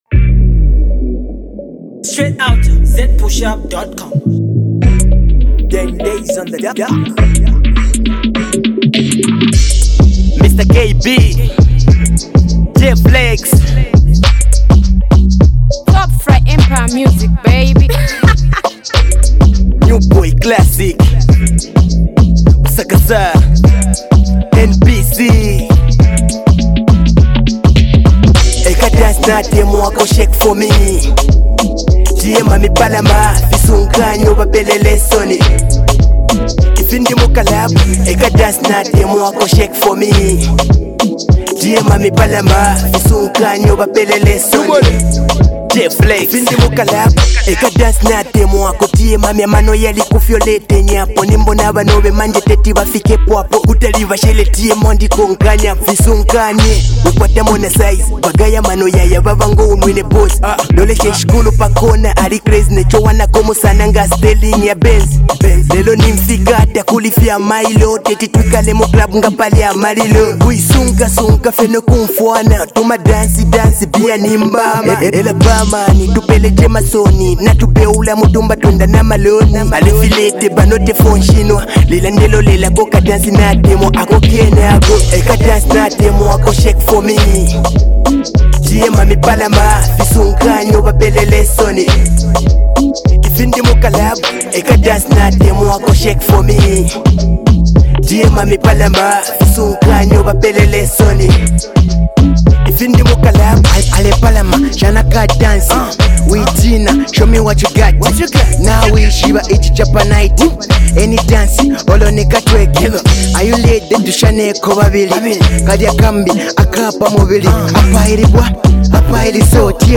The jam has some dope bars and a well-mastered beat